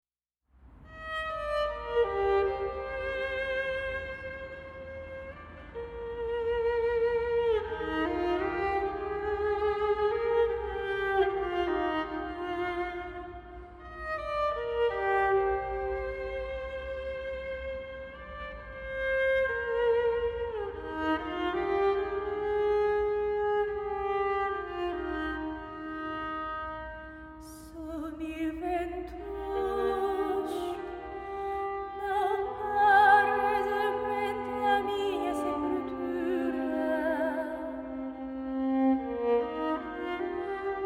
ポルトガル、聖母マリアの奇蹟、シスター・ルシアに導かれ、カルメロ修道院で奇跡の録音！